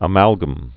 (ə-mălgəm)